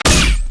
fire_tachyon2.wav